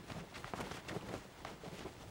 cloth_sail14.L.wav